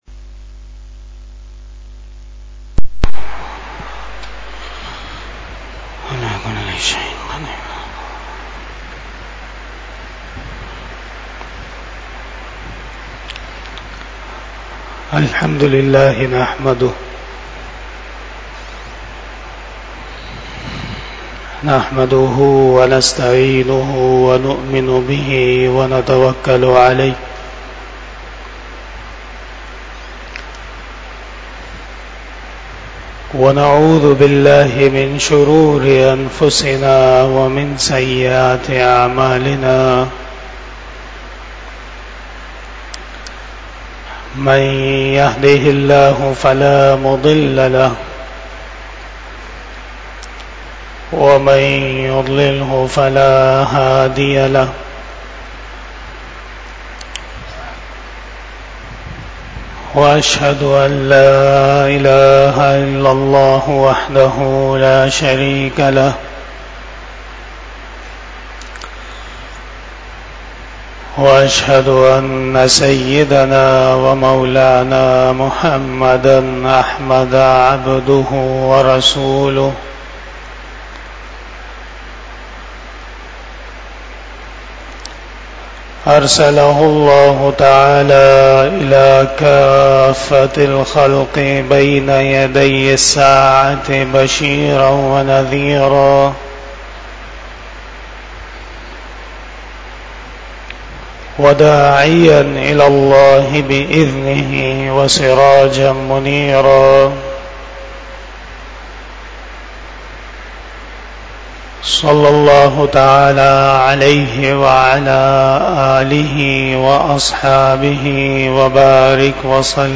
24 Bayan E Jummah 14 June 2024 (07 ZilHajjah 1445 HJ)